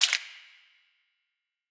Gone Snap.wav